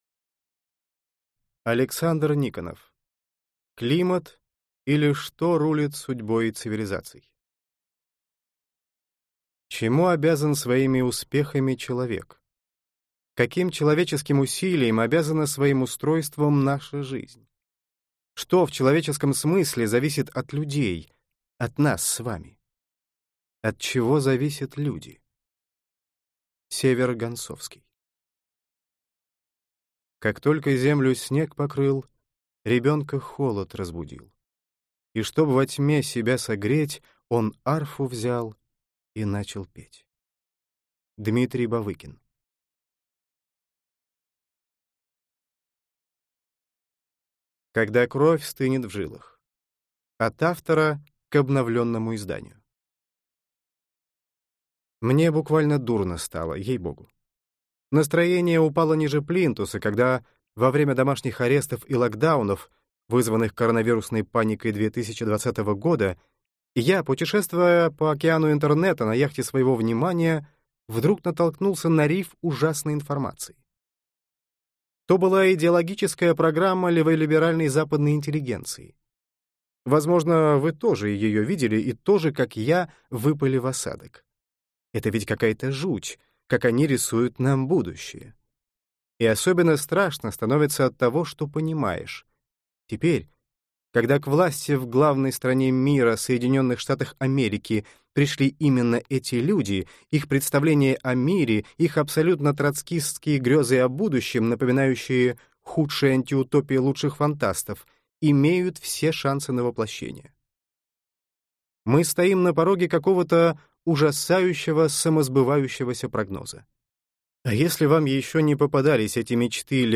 Аудиокнига Климат, или Что рулит судьбой цивилизаций | Библиотека аудиокниг
Прослушать и бесплатно скачать фрагмент аудиокниги